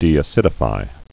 (dēə-sĭdə-fī)